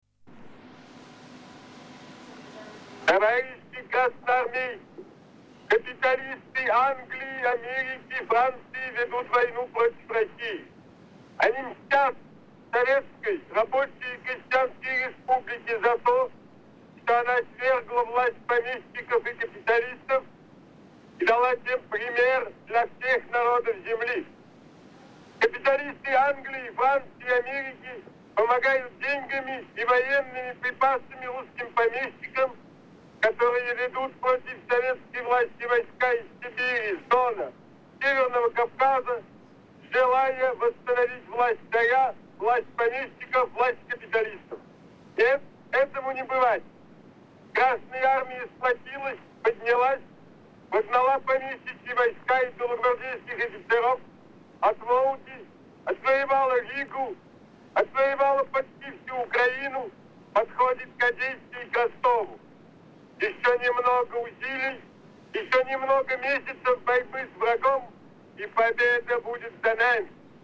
Brano di discorso all'Armata Rossa [mb 1 mp3]